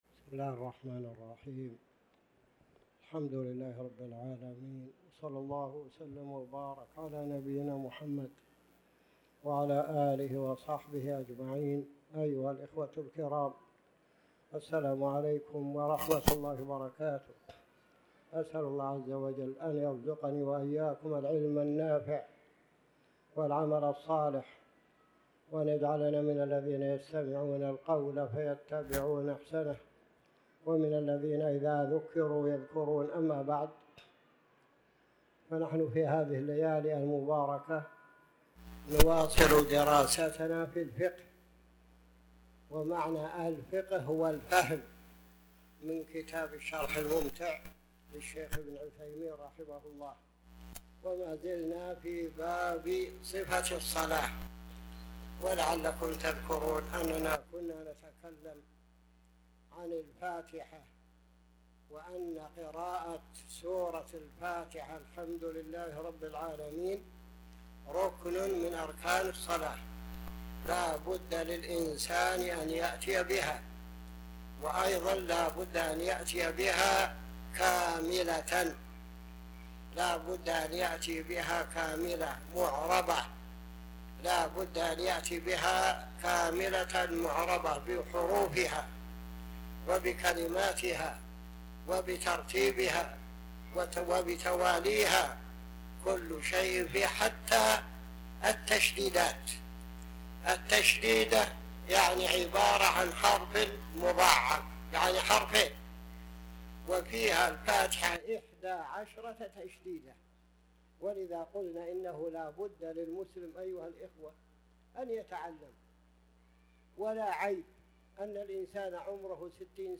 تاريخ النشر ٤ ذو القعدة ١٤٤٠ هـ المكان: المسجد الحرام الشيخ